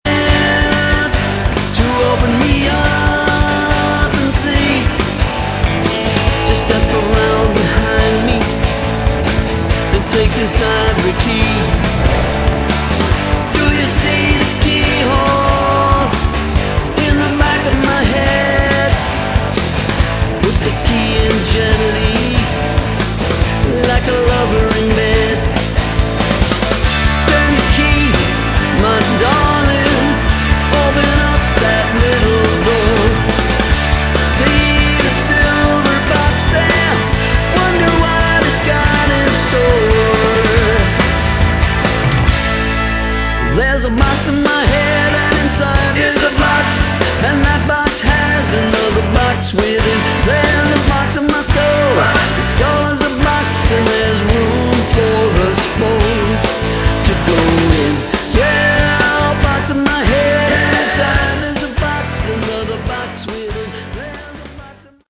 Category: Classic Hard Rock
lead vocals, guitars, keyboards
bass
drums